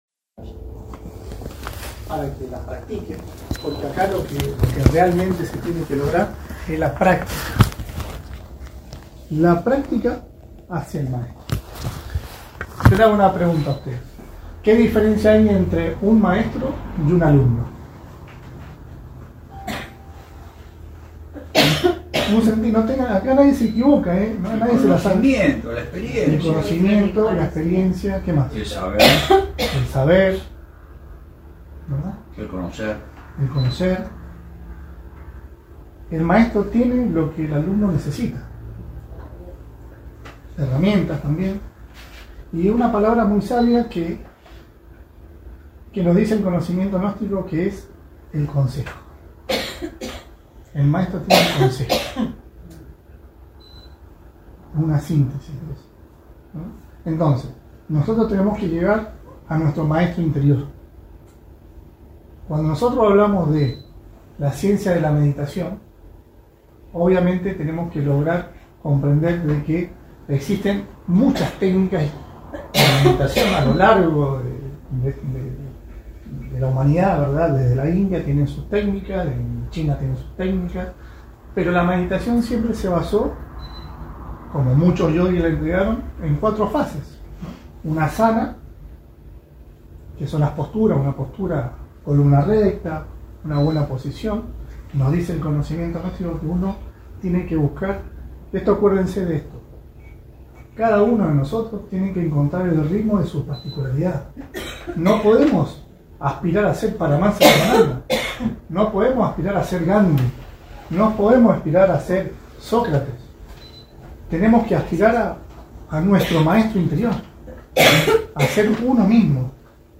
Conferencia Publica dictada en el Barrio de Playa Serena - Mar del Plata - Argentina Instructor